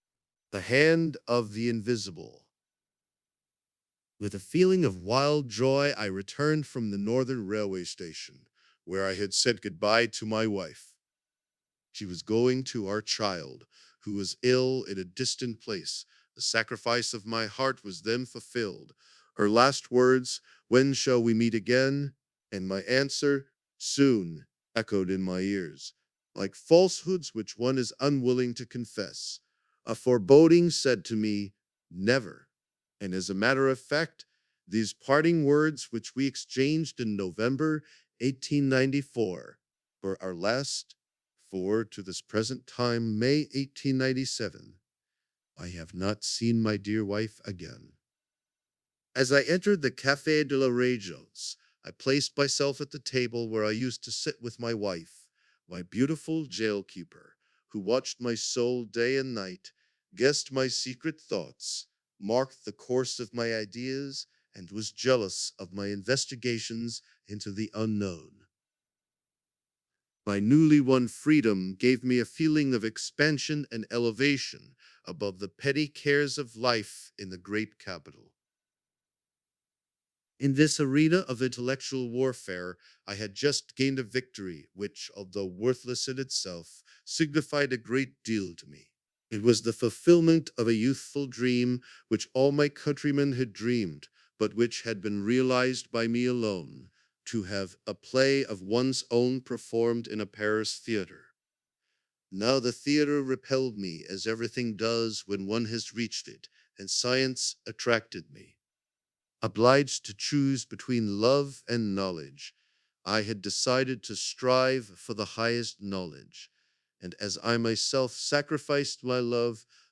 Long-form narration samples
Experience extended passages in English and German from Tontaube, the world's most natural-sounding model for audiobooks.
Audiobook narration — English
tontaubeV0-audiobook-en.m4a